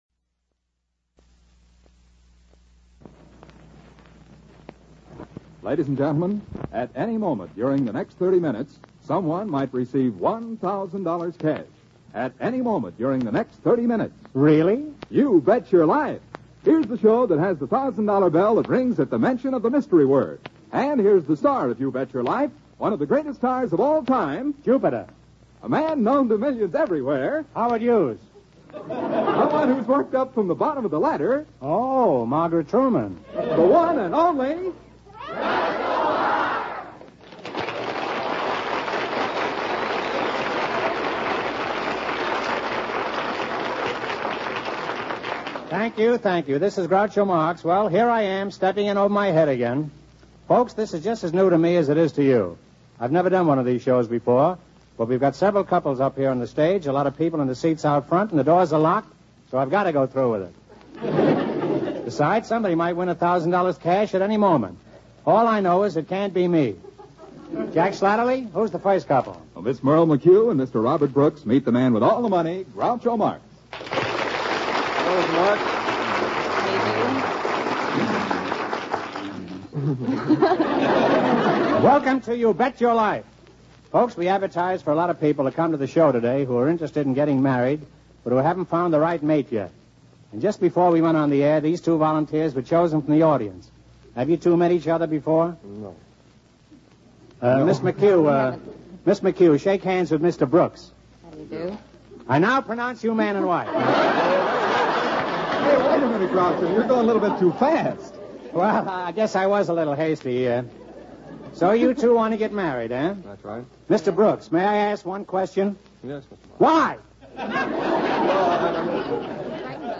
You Bet Your Life Radio Program, Starring Groucho Marx